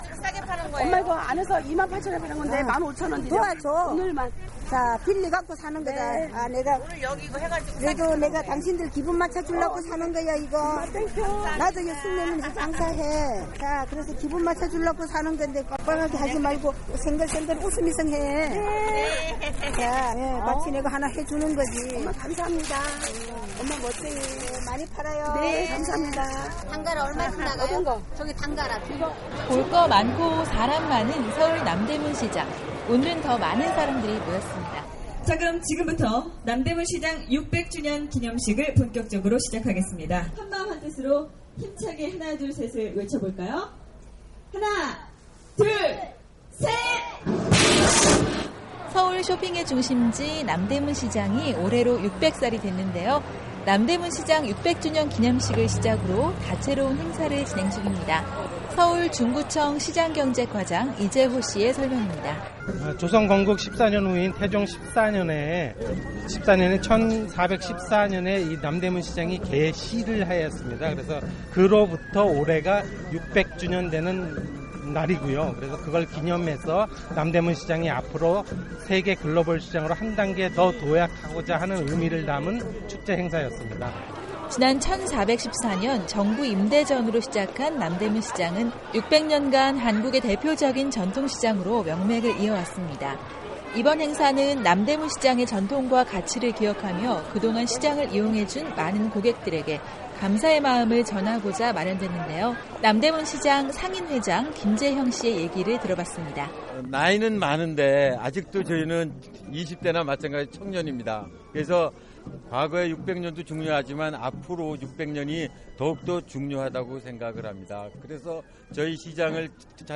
한국사회의 이모저모를 전해드리는 ‘안녕하세요 서울입니다’ 순서, 한국의 대표적인 전통시장인 남대문시장이 600주년을 올해로 맞았습니다 10월1일부터 3일까지 남대문시장에서는 600주년 기념식과 함께 다채로운 행사를 열었다고 하는데요 서울에서